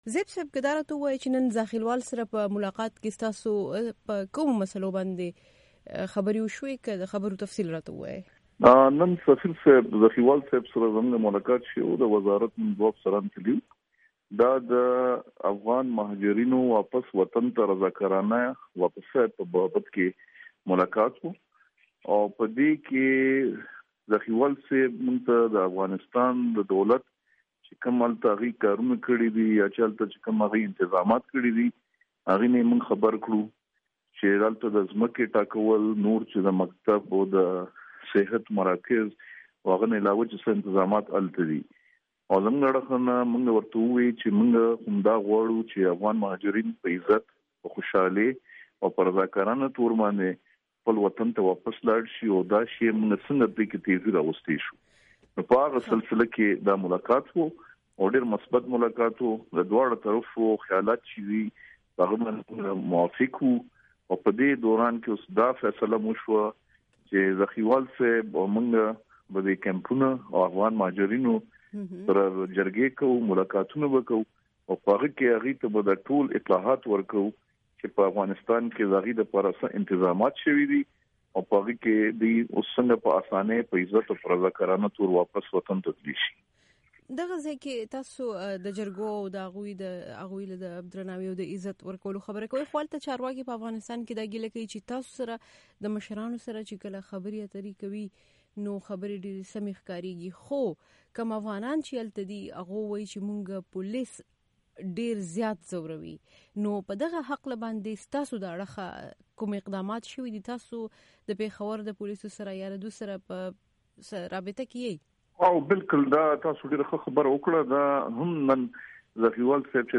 د افغان مهاجرینو دپاره د پاکستان عالي کمشنر ډاکټر عمران زیب سره مرکه